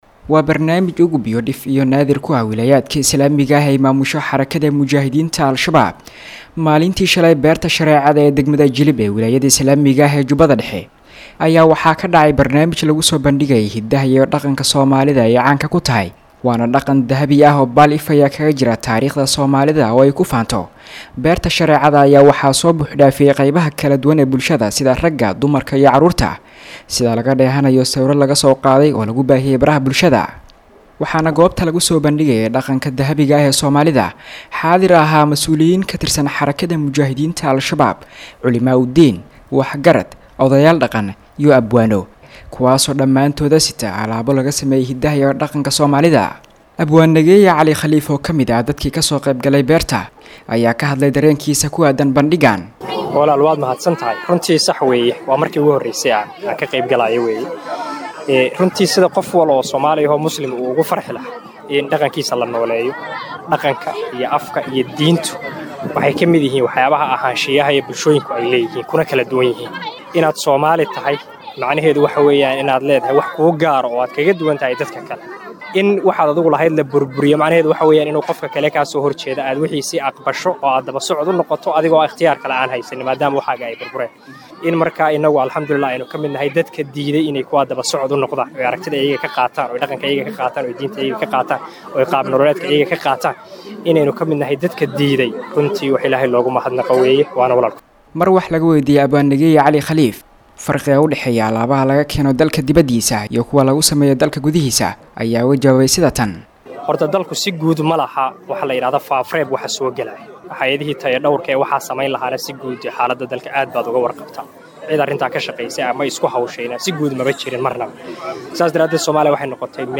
Bandhigga Hidaha iyo Dhaqanka Soomaalida oo Lagu Qabtay Magaalada Jilib.[WARBIXIN]